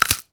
bone_break_neck_snap_crack_02.wav